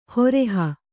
♪ horeha